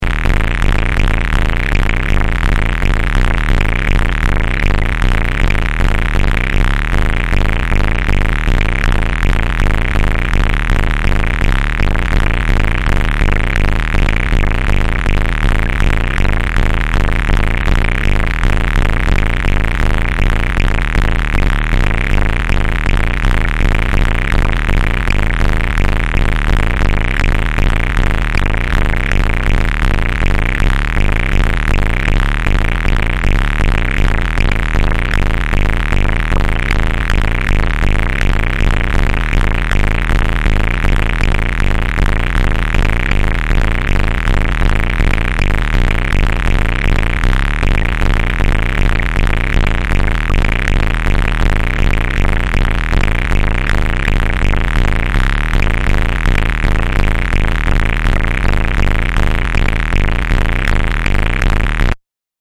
Subtle small pieces of noise. Made only with No Input Mixer in 2004.